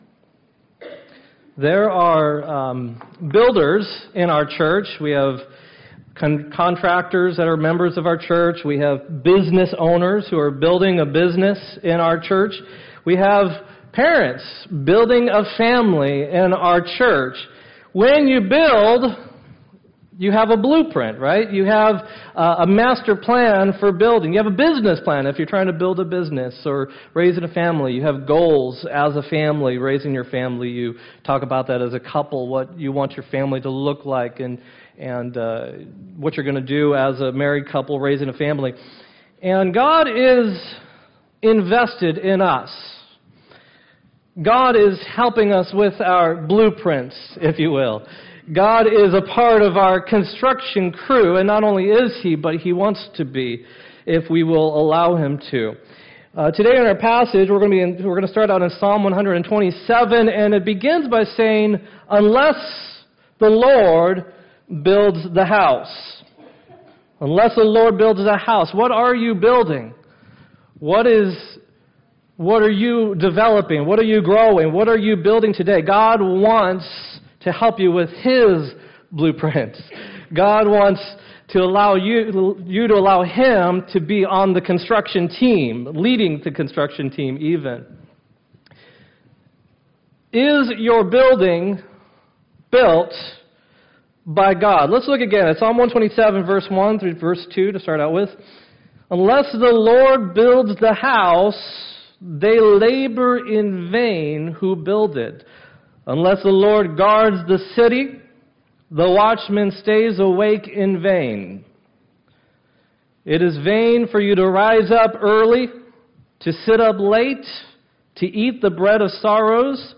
2-18-17 sermon